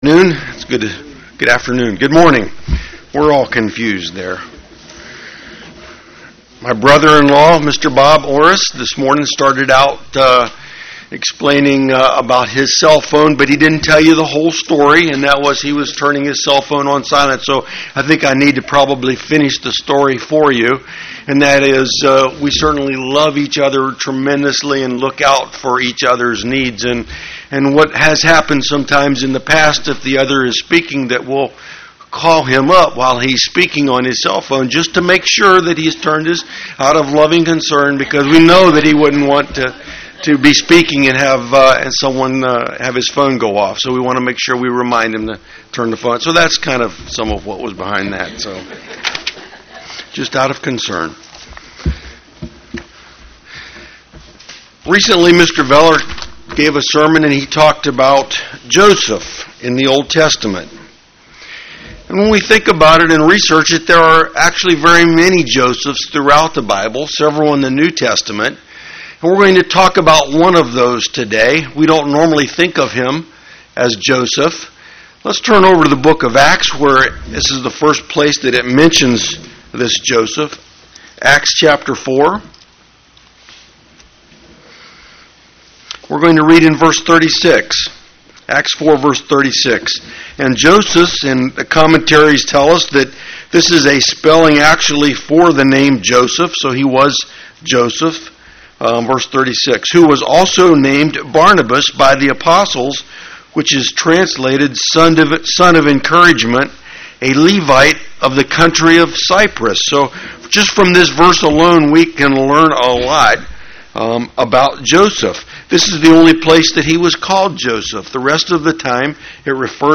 Given in St. Petersburg, FL
UCG Sermon Studying the bible?